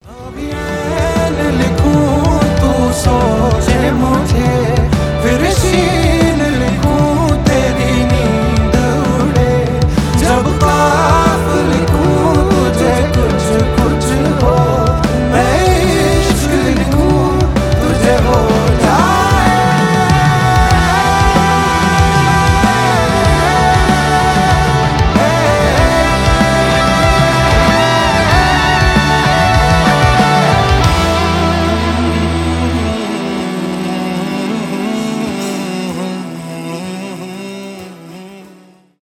дуэт , поп